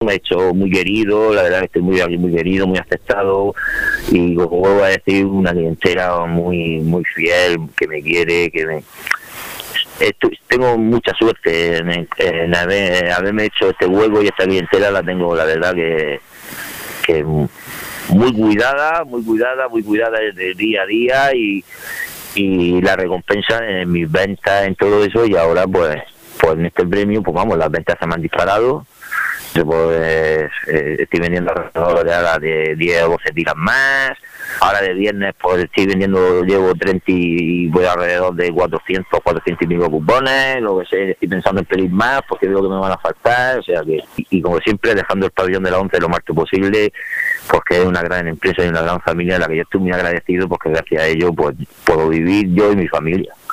Un hombre emotivo y espontáneo, de voz cantarina.